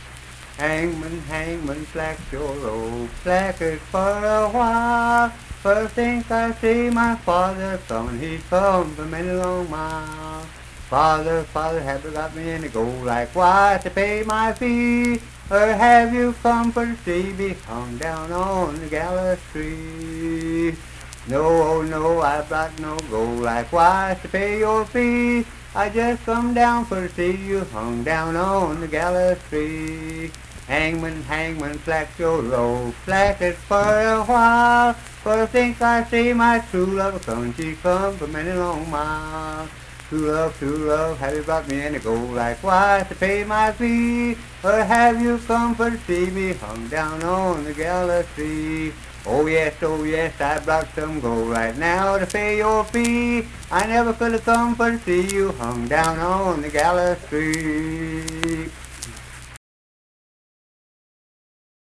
Unaccompanied vocal and banjo music
Voice (sung)
Clay (W. Va.), Clay County (W. Va.)